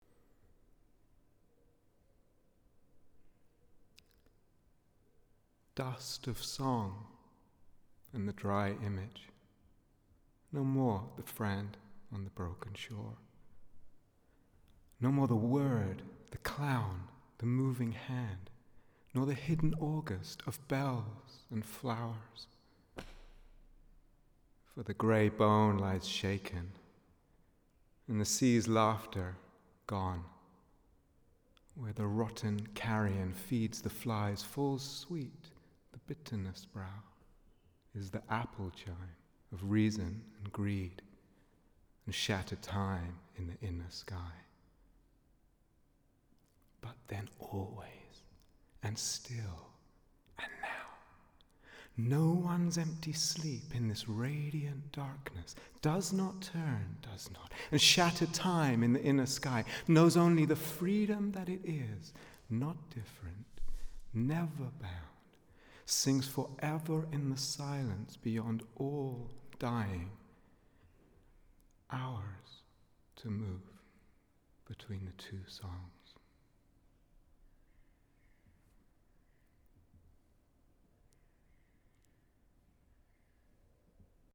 Amāra Vigil (for solo piano, 2000)